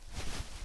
wool.ogg